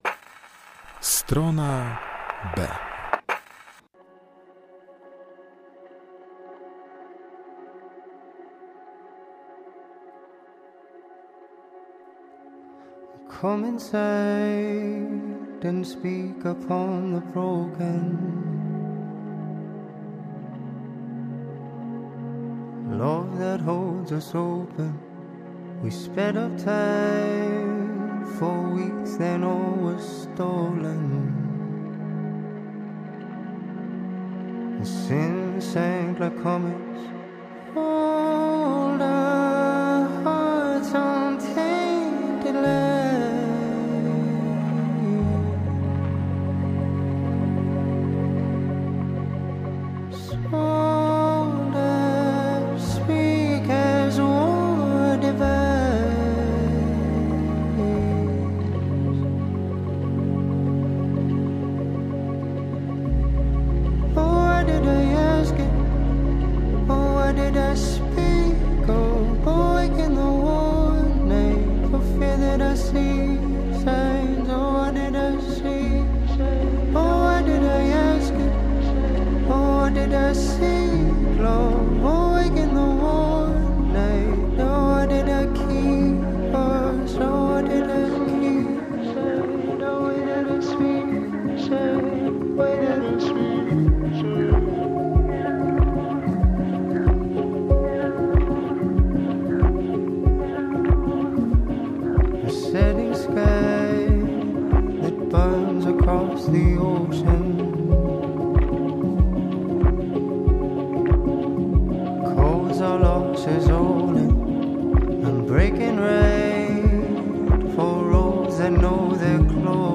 Bez gadania - tylko muzyczne wyciszenie...